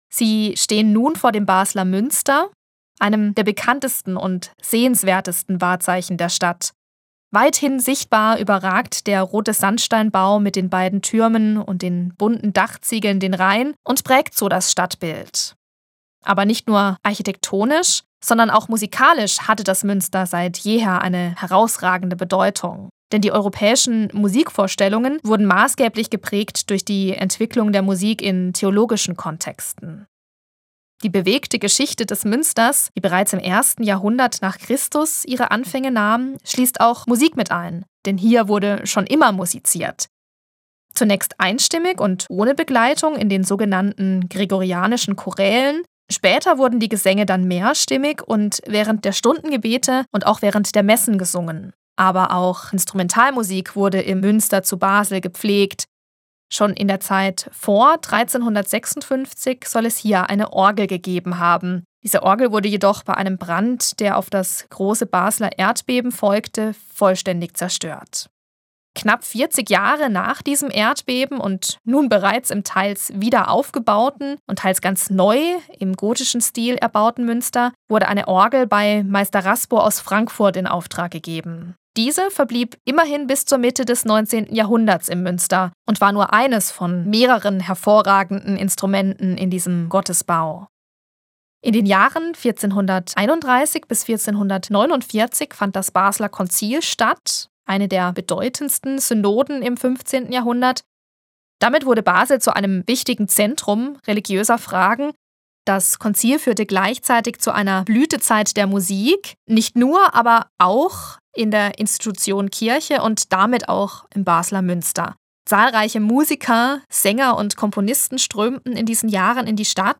QUELLEN der Musikbeispiele:
Mathis-Orgel